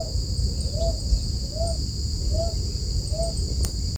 Picui Ground Dove (Columbina picui)
Condition: Wild
Certainty: Observed, Recorded vocal